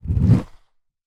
SD_SFX_RollerSkate_Land.wav